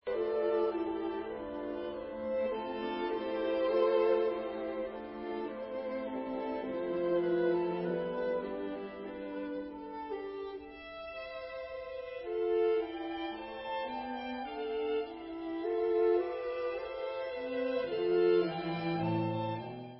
pro smyčcový orchestr